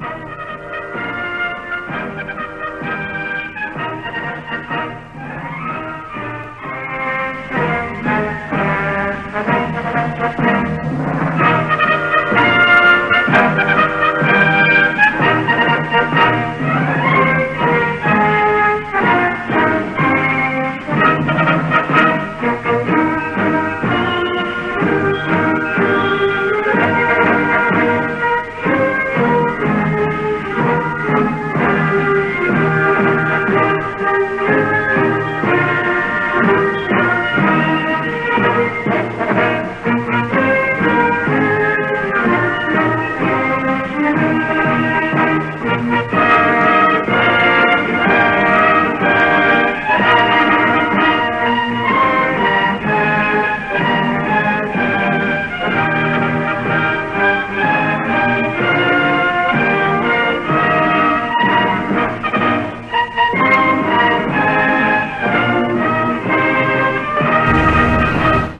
1983년 공연